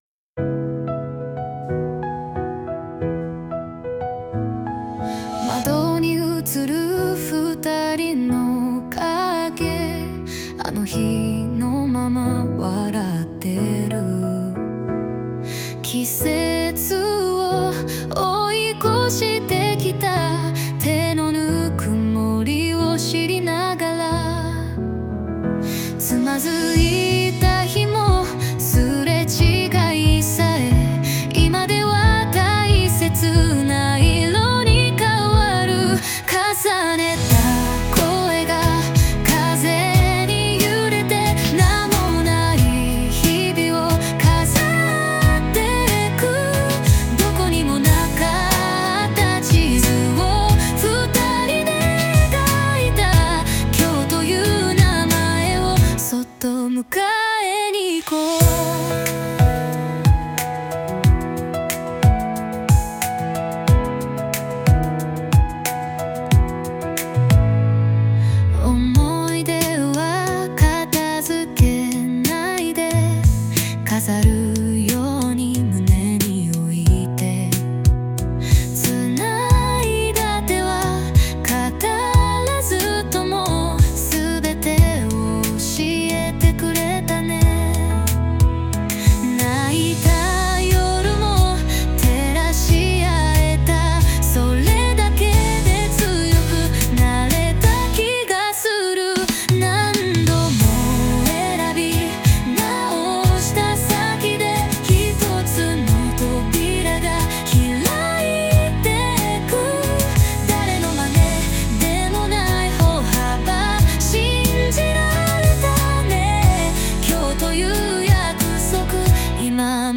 邦楽女性ボーカル著作権フリーBGM ボーカル
著作権フリーオリジナルBGMです。
女性ボーカル（邦楽・日本語）曲です。